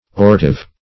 Search Result for " ortive" : The Collaborative International Dictionary of English v.0.48: Ortive \Or"tive\, a. [L. ortivus, fr. oriri, ortus, to rise: cf. F. ortive.]